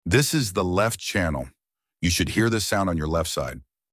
LEFT CHANNEL